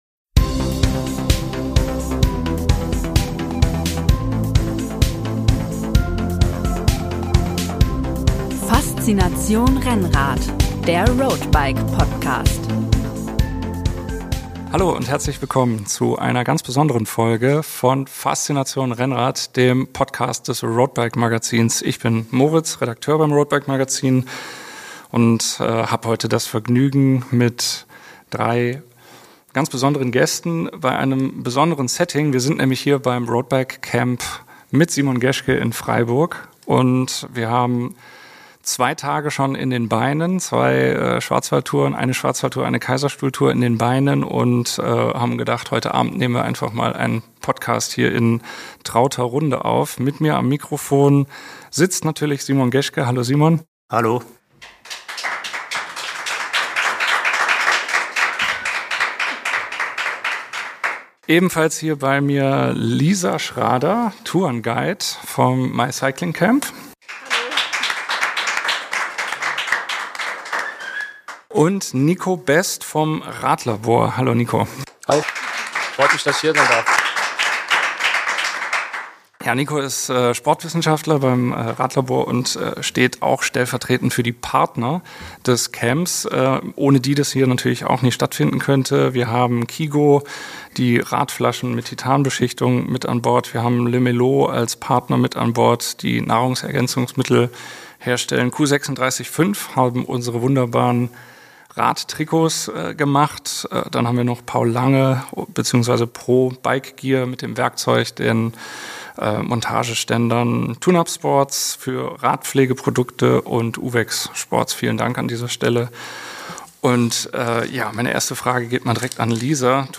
Unser erster Podcast vor Publikum! Was macht Tour-Etappensieger Simon Geschke sechs Monate nach seinem Karriereende? Wie führt man eine Gruppe, wie gibt man wertschätzend Tipps?